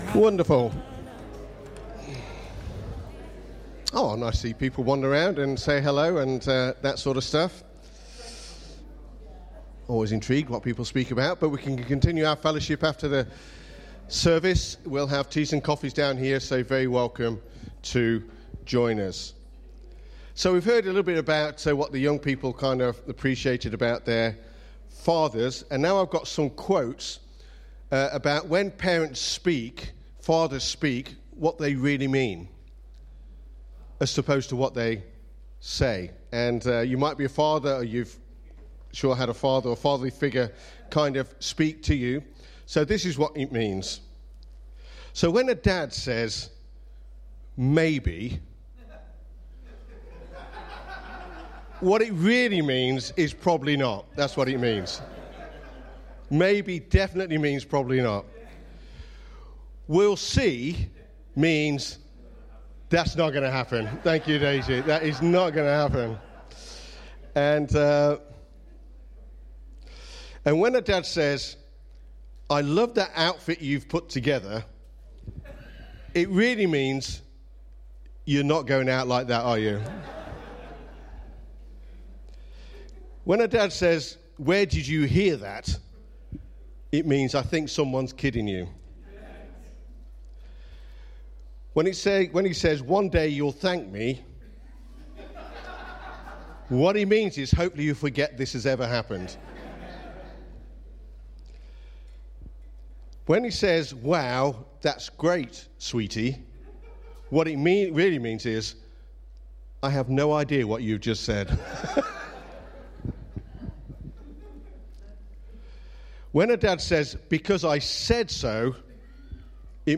2024 Father’s day Preacher